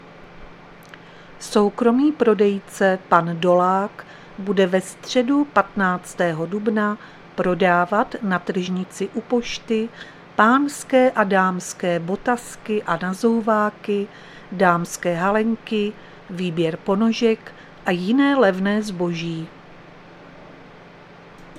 Záznam hlášení místního rozhlasu 15.4.2025